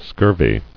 [scur·vy]